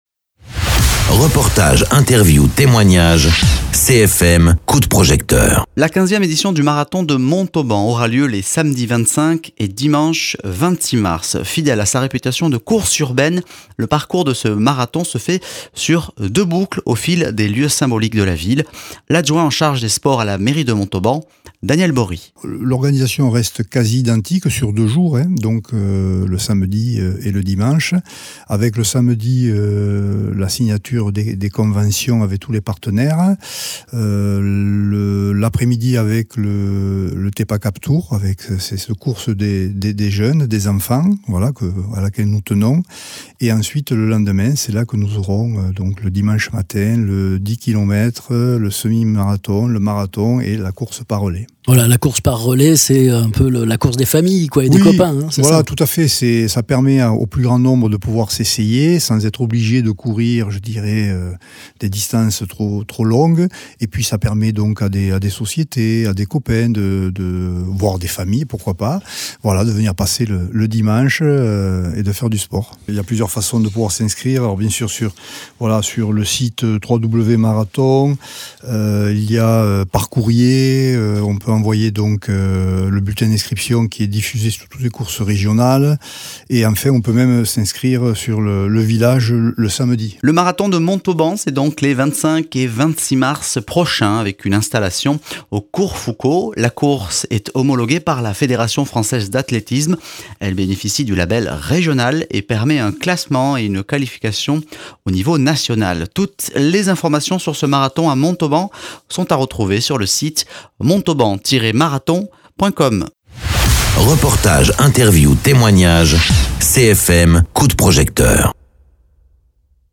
Daniel Bory, adjoint aux sports à la mairie de Montauban présente le programme du marathon de Montauban
Interviews